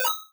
fnl/Assets/Extensions/Advanced_UI/User_Interface/Casino/Casino Bet.wav
Casino Bet.wav